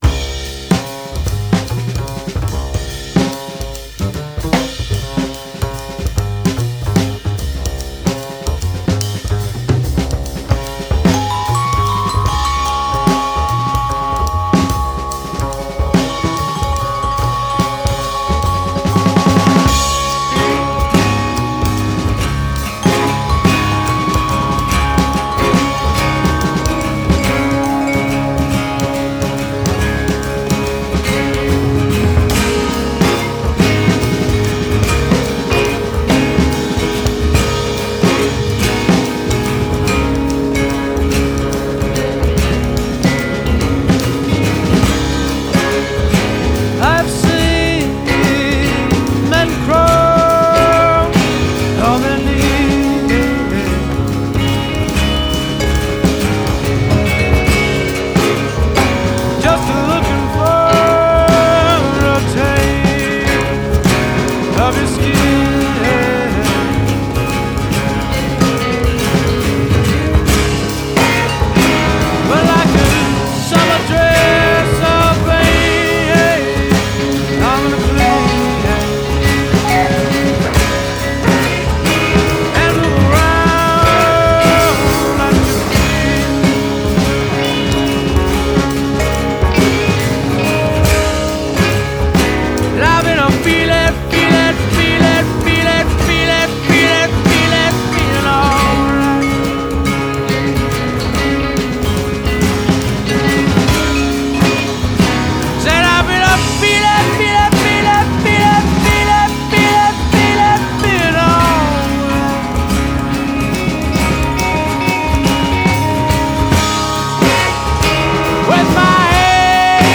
chromatic finger-picking style